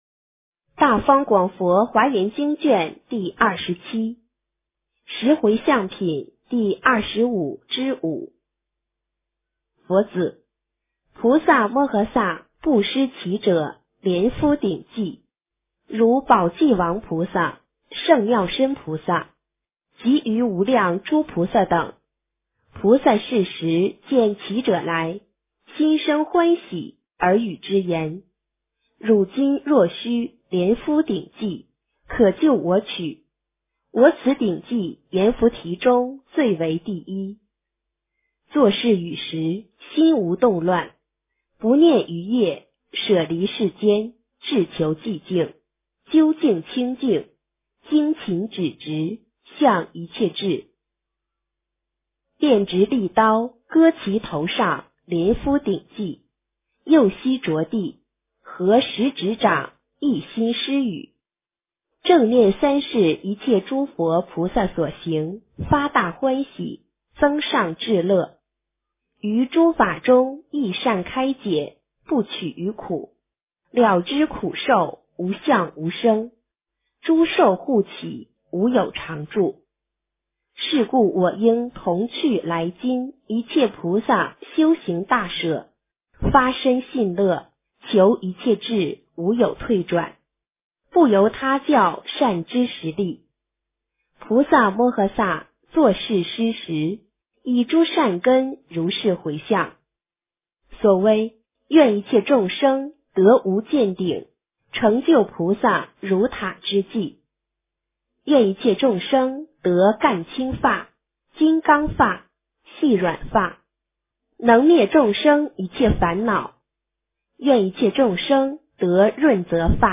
华严经27 - 诵经 - 云佛论坛